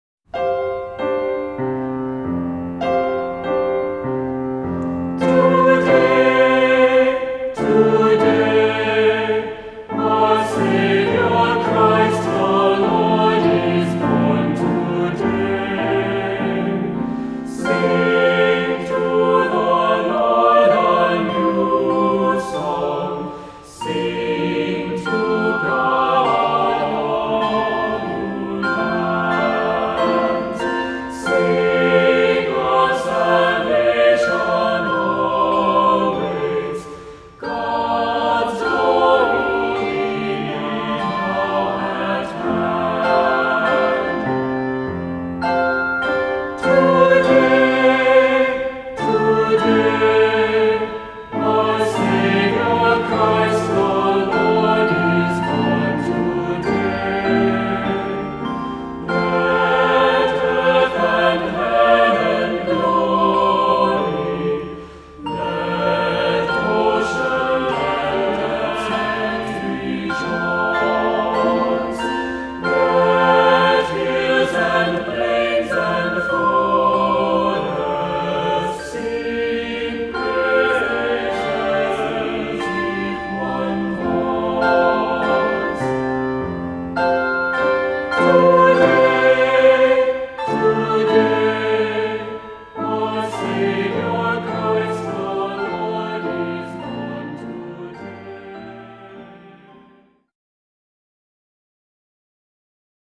Voicing: Descant, cantor,SATB, assembly